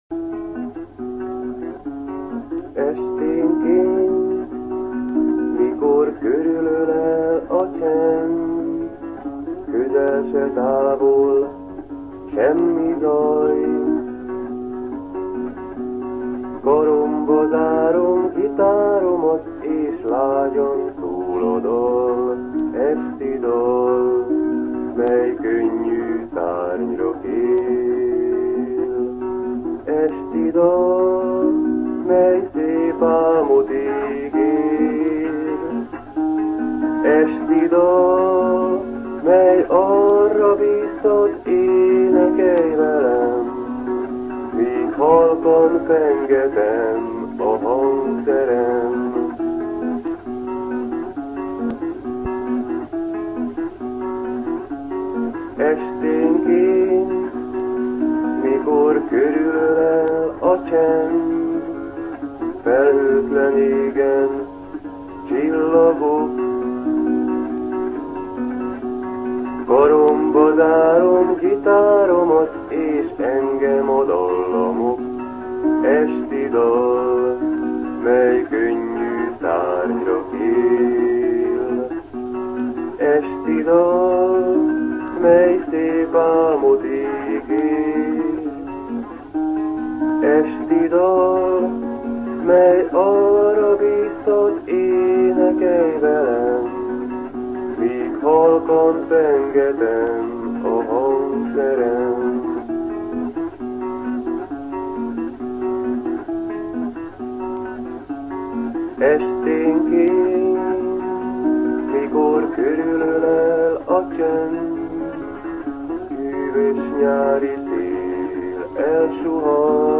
Később a magam és környezetem szórakoztatására gitároztam, dalokat írtam.
A hangminőség hagy kívánnivalót maga után, csak a nagyon kíváncsiak és nagyon bátrak hallgassák meg.